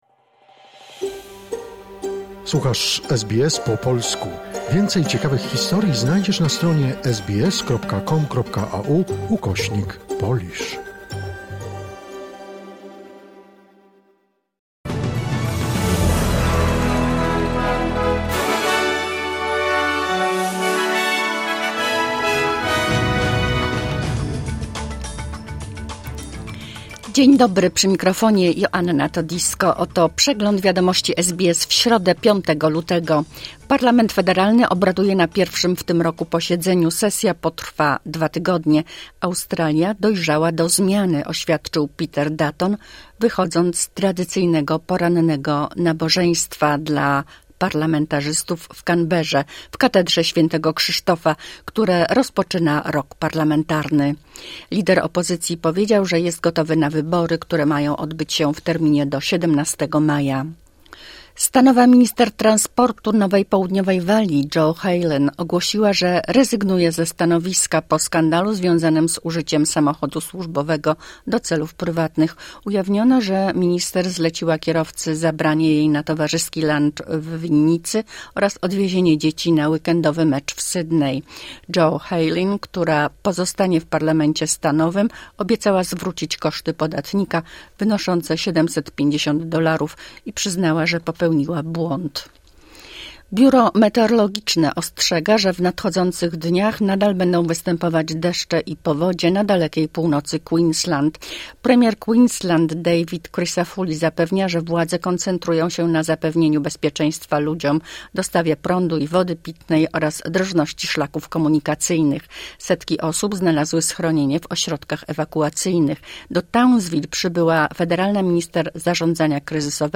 Skrót najważniejszych doniesień z Australii i ze świata, w opracowaniu polskiej redakcji SBS